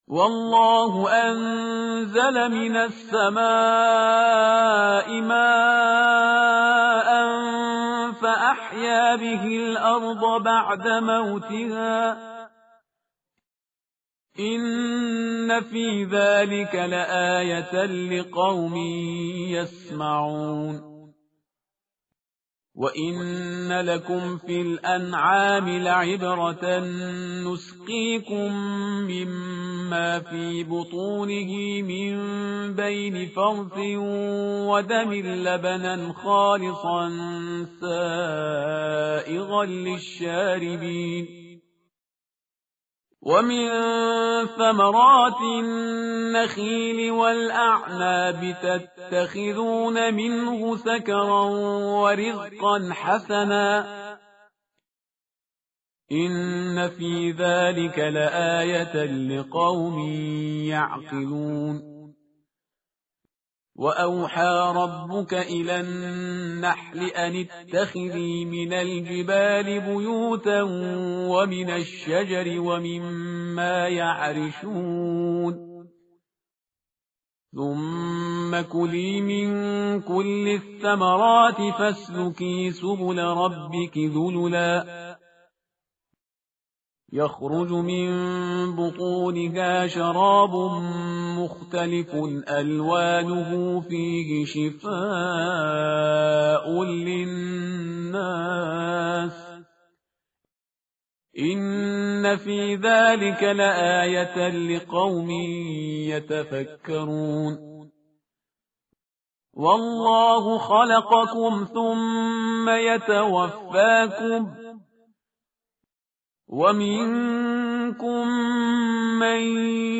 tartil_parhizgar_page_274.mp3